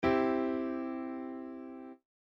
A C Major triad is spelled C-E-G.
C Major
In a general sense, many people think of major as sounding “happy” and minor as sounding “sad”.
C_Major.mp3